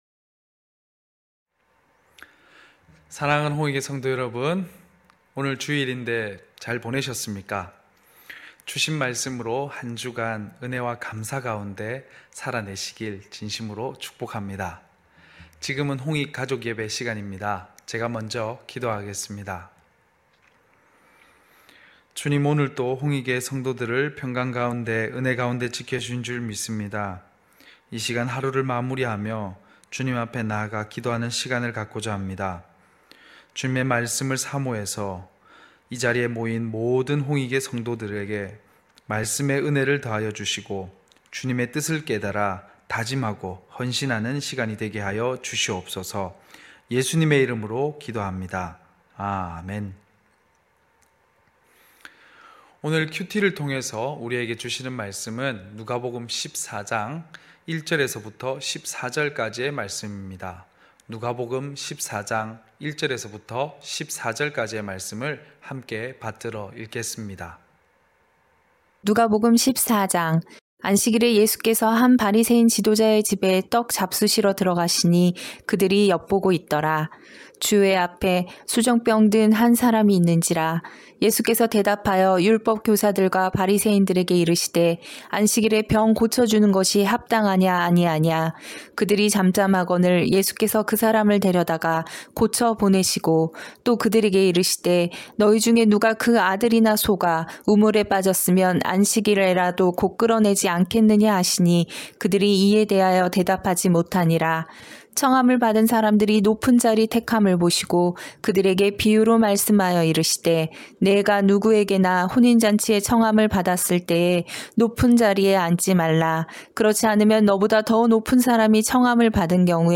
9시홍익가족예배(2월14일).mp3